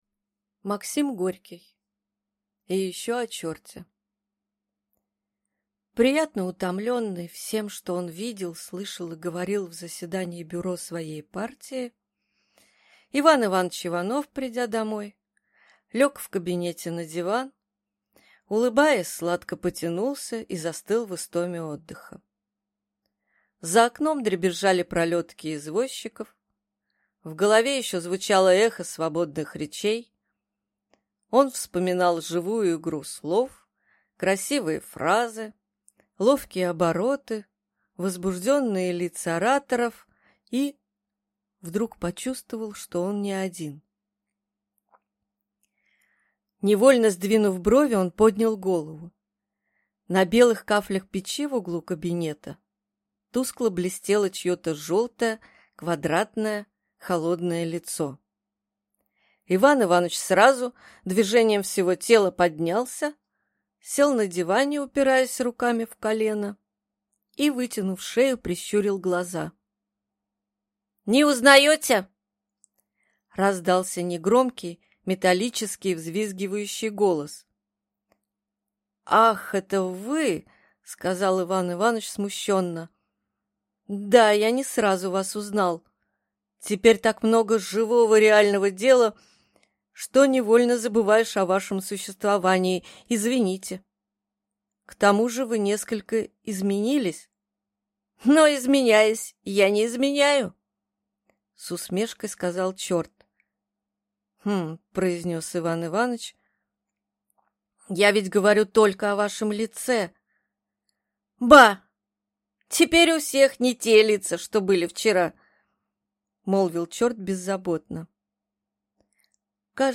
Aудиокнига И еще о черте